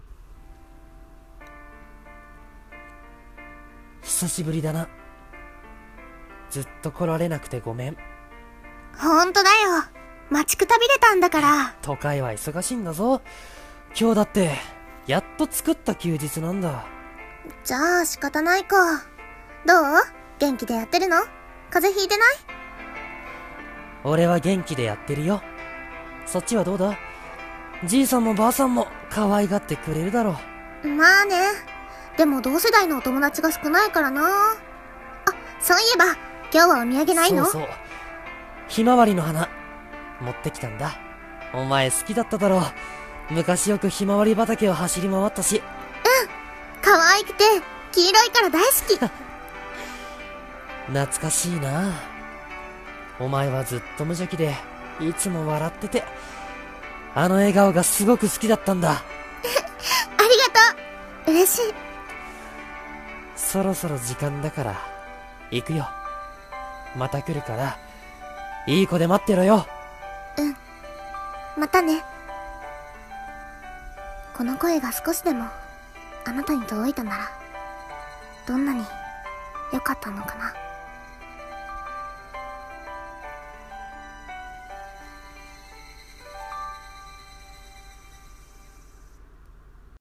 二人声劇台本「墓参り」